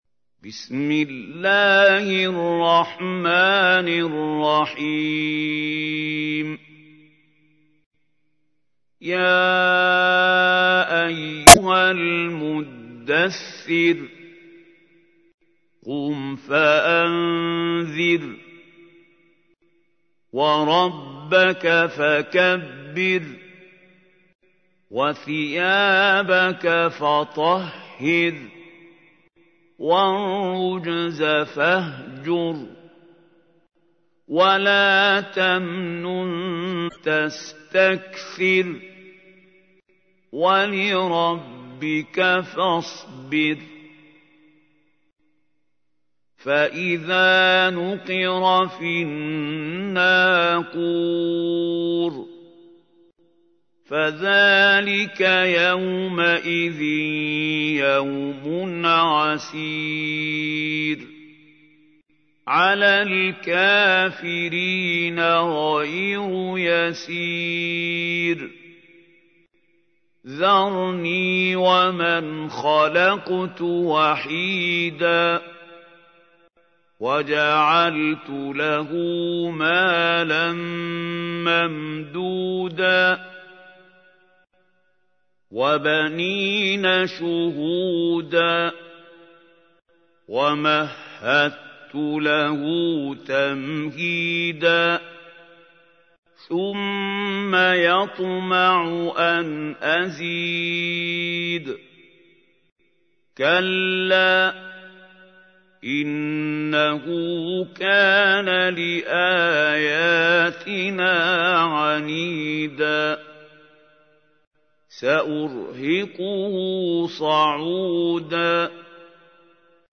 تحميل : 74. سورة المدثر / القارئ محمود خليل الحصري / القرآن الكريم / موقع يا حسين